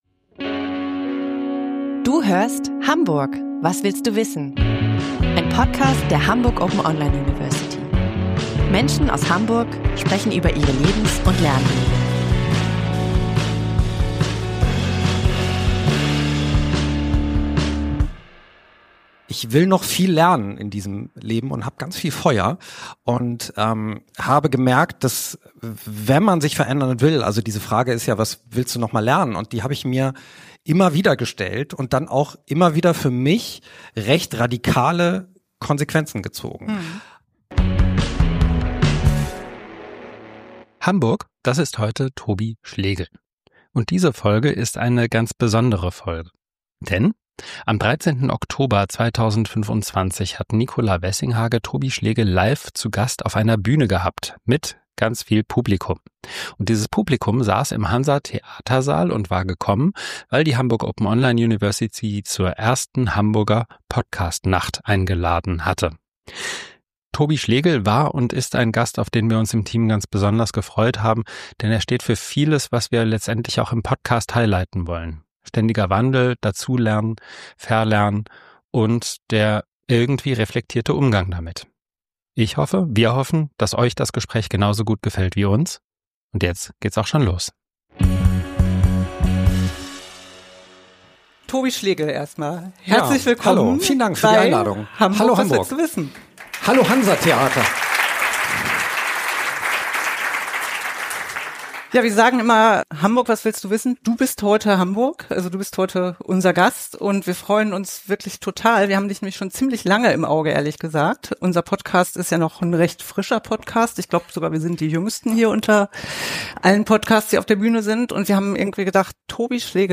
Beschreibung vor 5 Monaten Tobi Schlegl passt perfekt in einen Podcast, in dem es um Veränderung, Lernen und Verlernen geht. Deswegen haben wir uns sehr gefreut, ihn ausgerechnet im Rahmen der ersten Hamburger Podcast-Nacht auf der Bühne im Hansa-Theatersaal willkommen zu heißen.